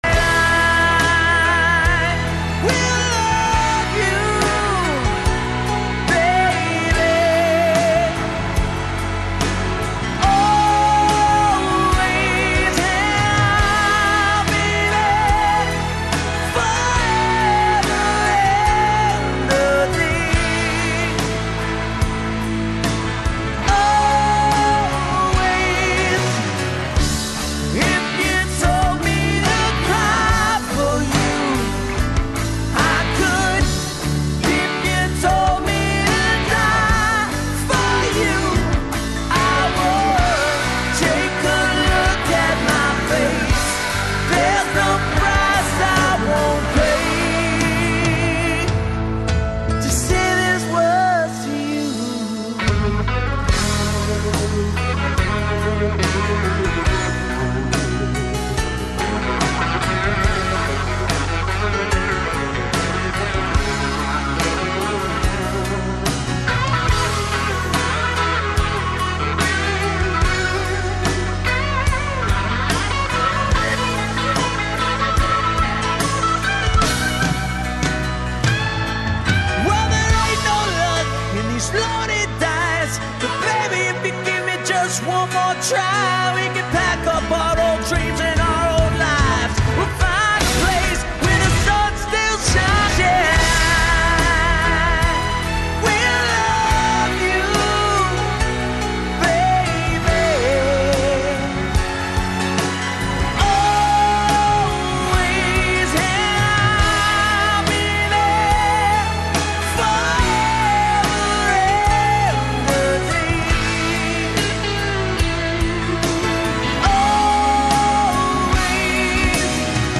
Неки од учесника конференције су и гости емисије У средишту пажње.